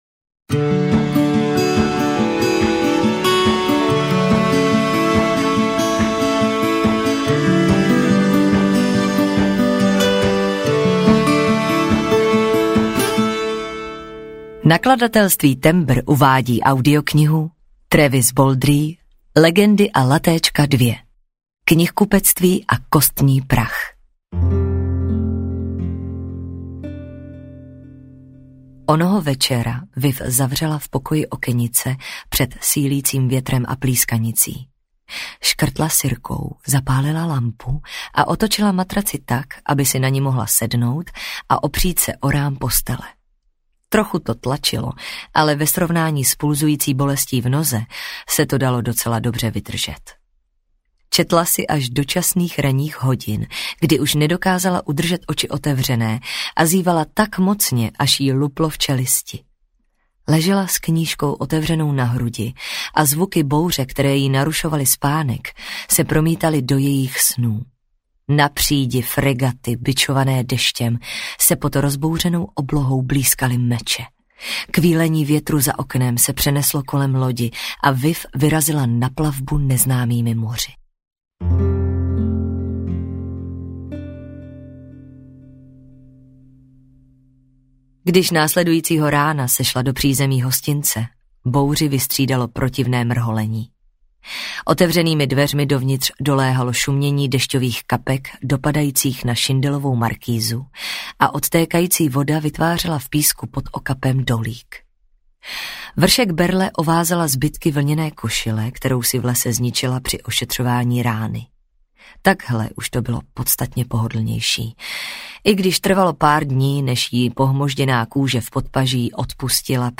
Ukázka z knihy
Natočeno ve studiu KARPOFON (AudioStory)